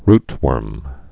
(rtwûrm, rt-)